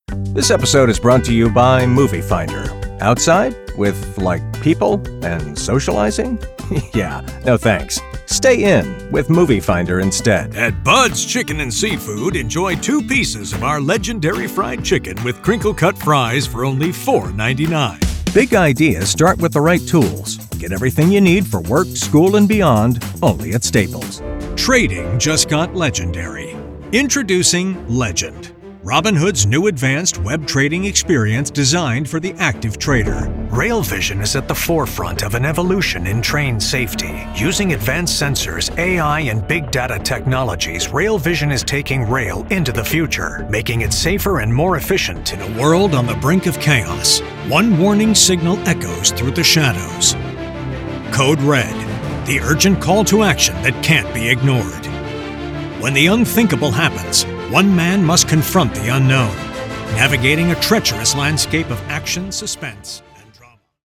English (American)
Articulate Conversational Authoritative